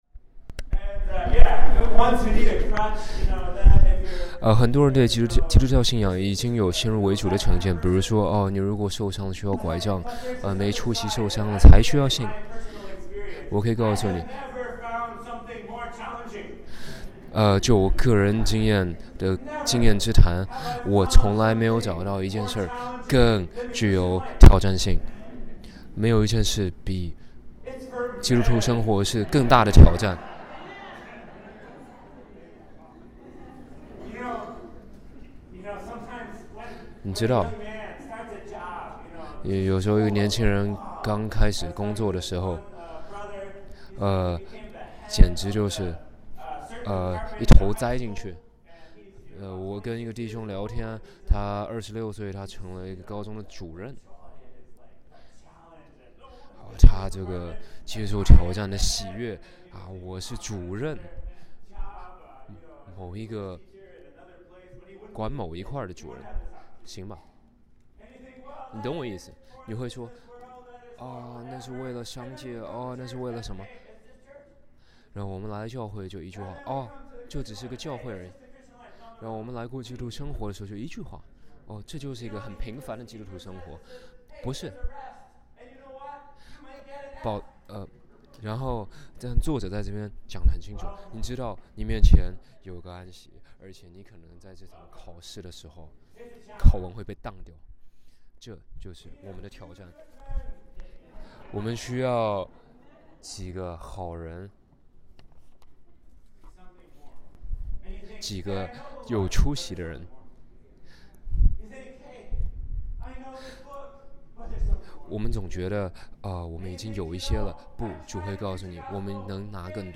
2013.7.7 Jubilee 禧年聚会